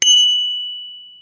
Neuzer acél pengetős csengő, iránytűvel, fekete
a csengő megfelelően hangos, az iránytő praktikus